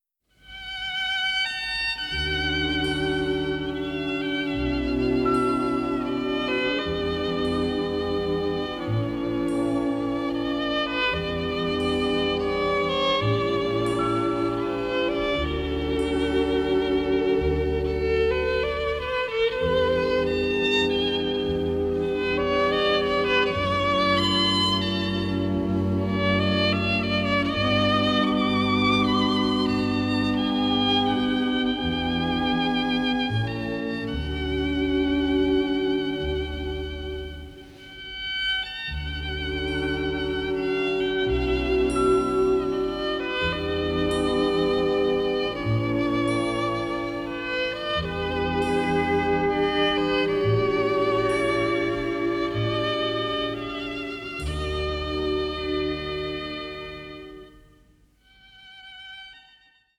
including the tango and Charleston